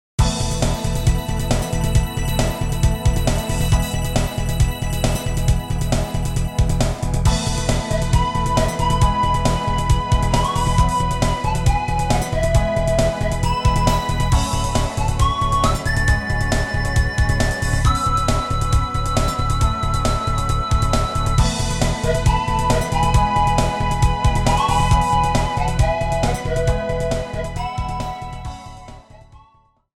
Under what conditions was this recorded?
added fade out